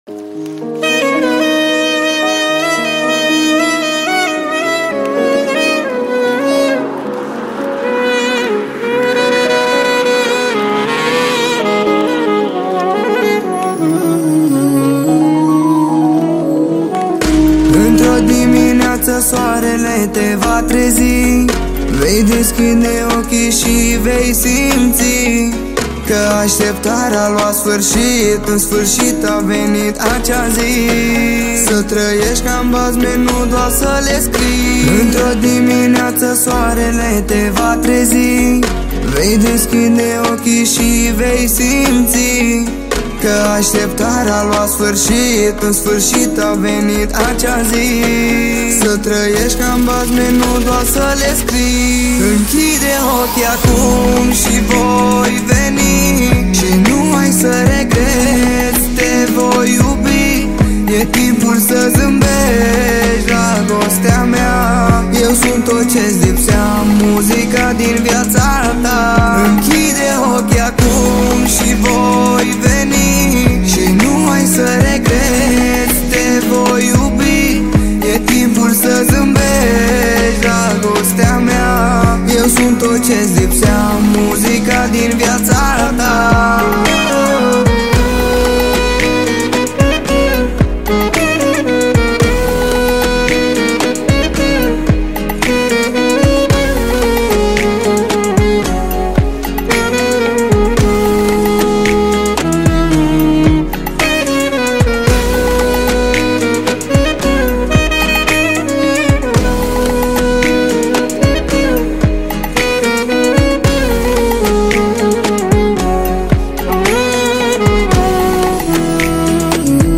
Data: 08.10.2024  Manele New-Live Hits: 0